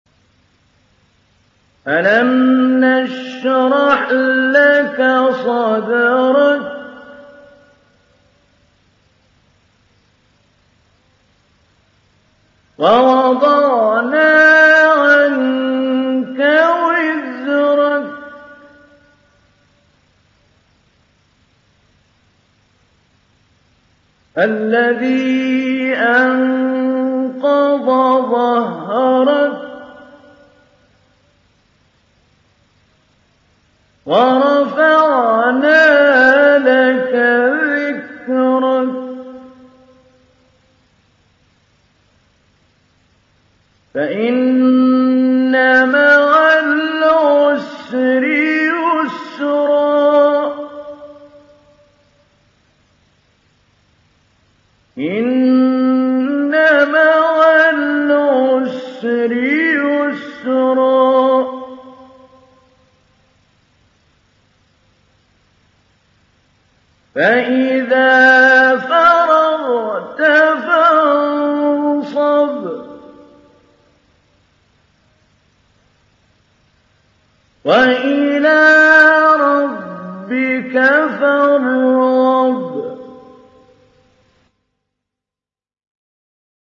Sourate Ash Sharh mp3 Télécharger Mahmoud Ali Albanna Mujawwad (Riwayat Hafs)
Télécharger Sourate Ash Sharh Mahmoud Ali Albanna Mujawwad